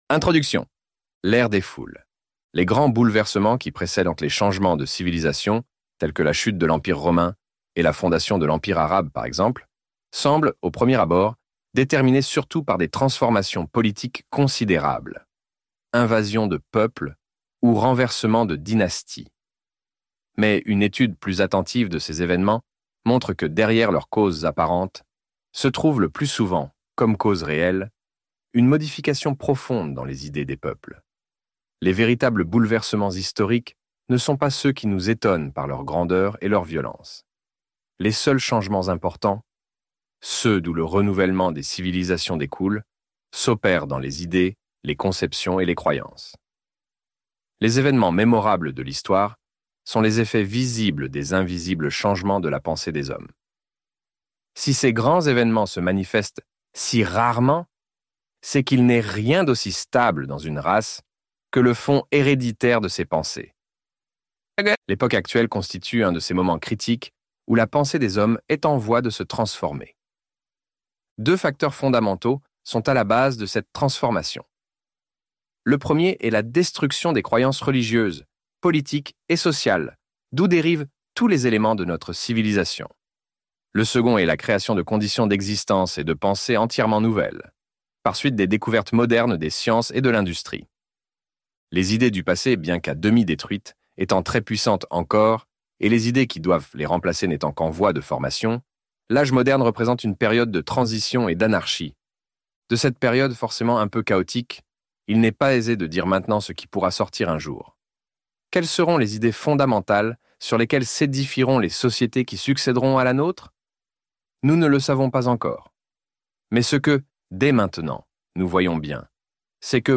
Psychologie des Foules - Livre Audio